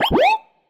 alarm_siren_loop_12.wav